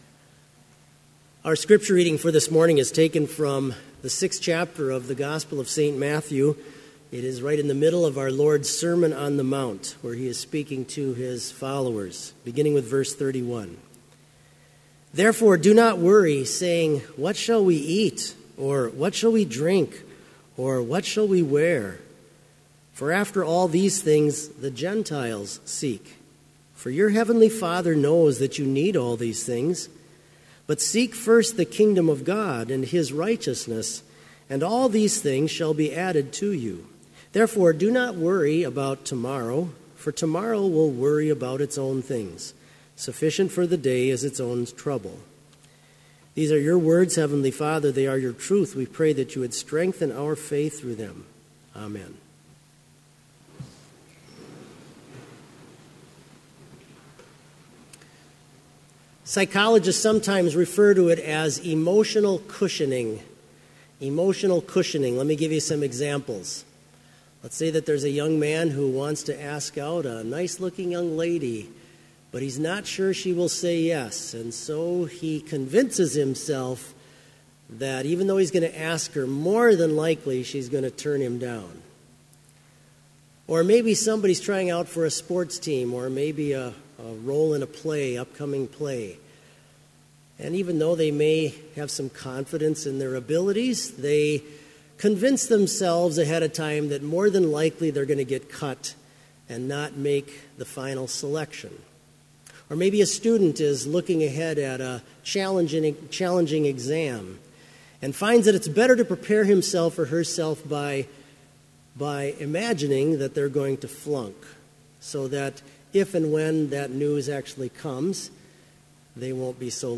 Complete service audio for Chapel - September 6, 2016